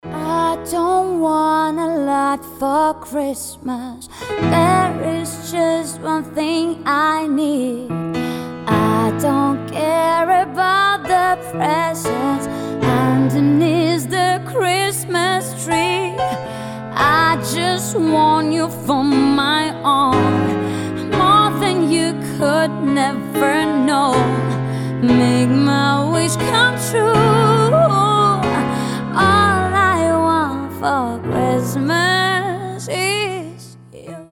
• Качество: 320, Stereo
поп
женский вокал
пианино
романтичные
красивый женский голос
волшебные
рождественские